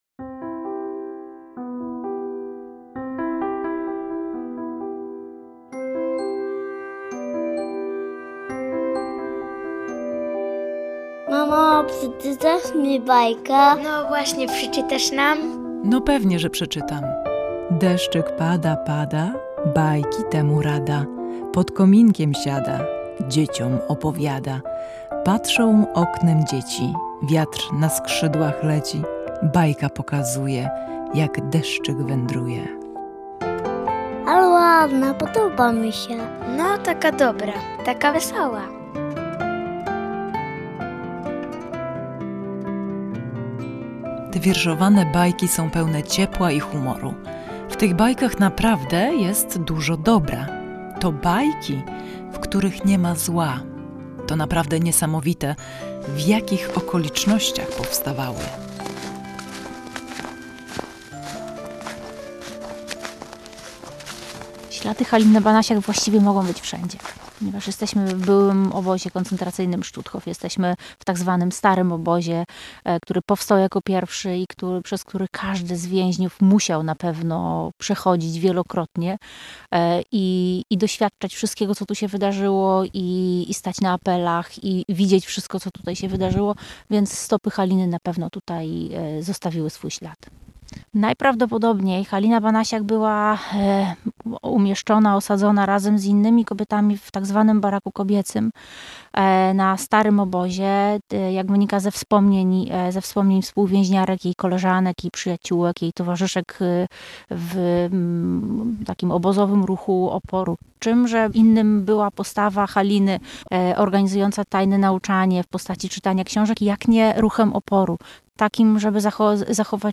Posłuchaj wzruszającego reportażu „Bajki ze Stutthofu” o sile dobra w człowieku